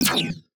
UIClick_Menu Negative Laser Shot 02.wav